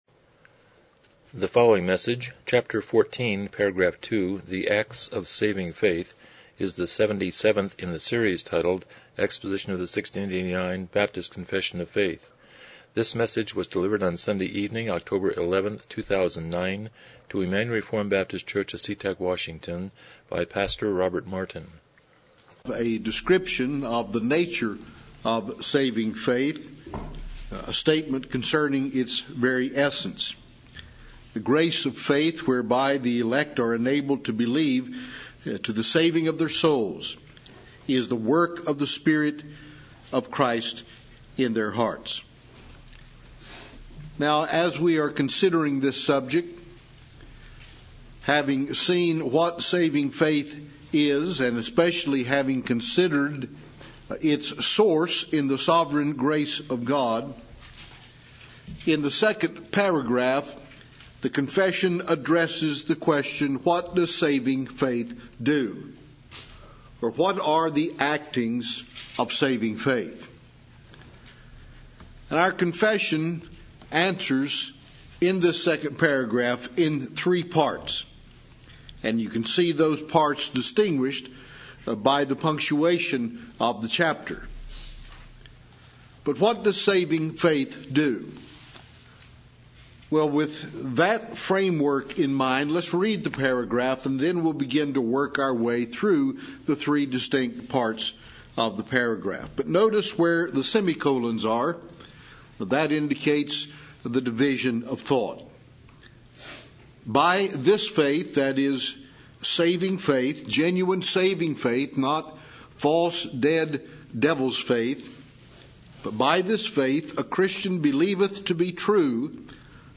1689 Confession of Faith Service Type: Evening Worship « 94 Romans 8:12-13